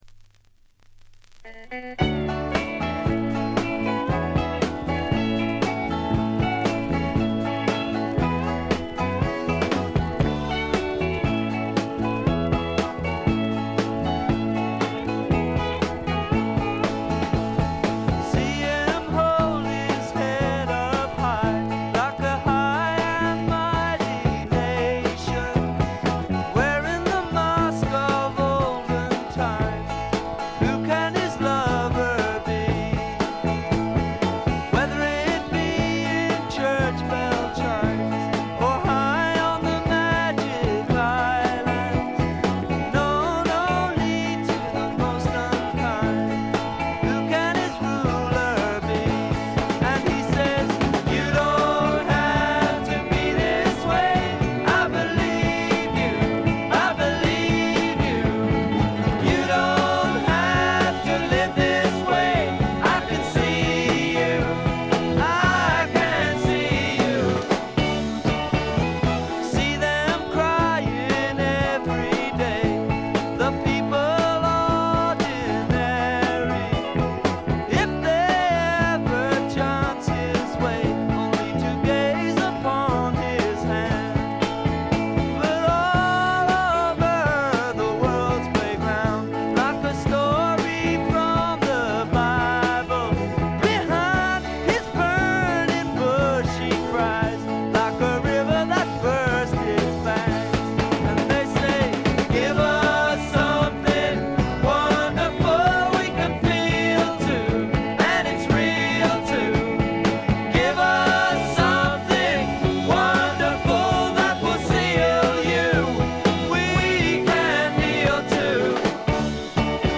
静音部で軽微なチリプチやバックグラウンドノイズ。
非トラッド系英国フォーク至宝中の至宝。
試聴曲は現品からの取り込み音源です。